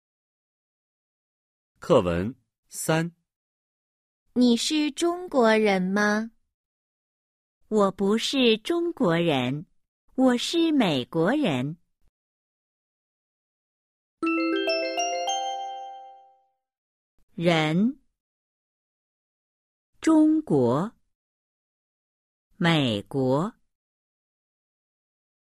#3. Hội thoại 3: Hỏi Quốc tịch 💿 03-03